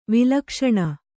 ♪ vilakṣaṇa